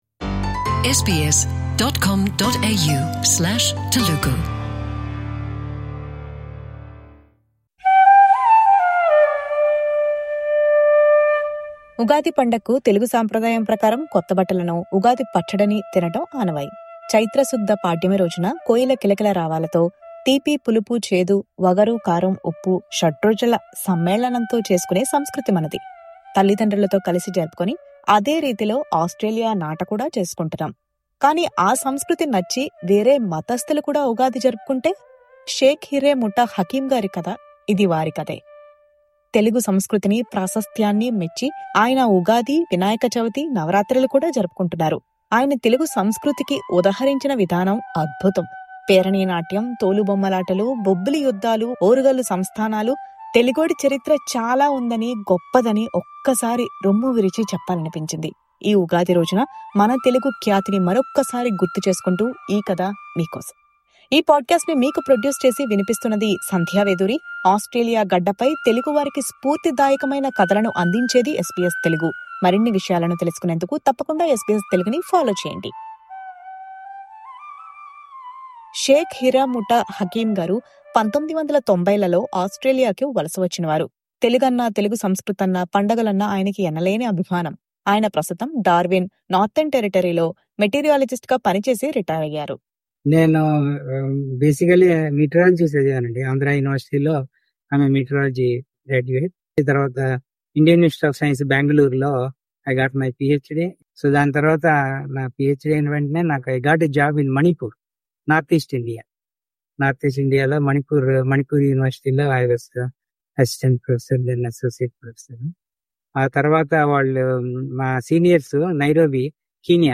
ఉగాది పండుగ సందర్బంగా, ఈ ప్రత్యేక ఇంటర్వ్యూలో